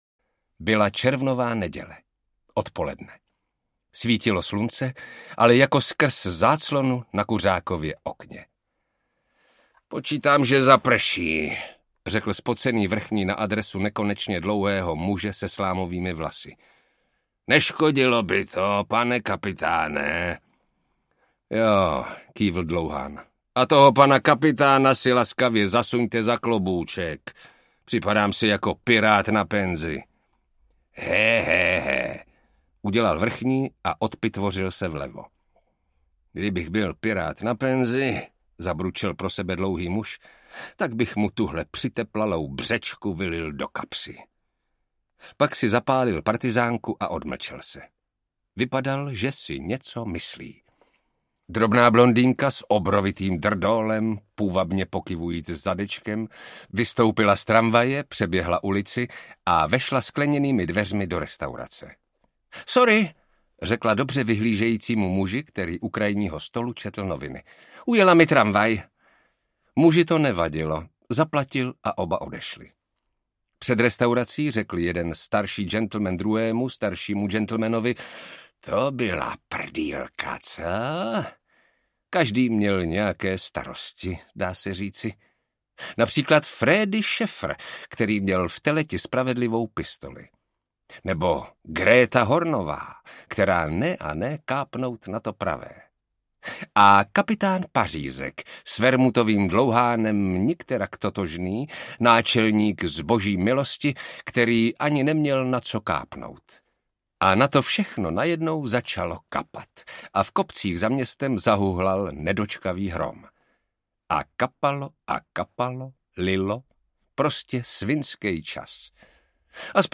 Spravedlivá pistole audiokniha
Ukázka z knihy
Vyrobilo studio Soundguru.